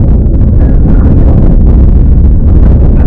ball.wav